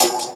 Track 02 - Percussion OS 05.wav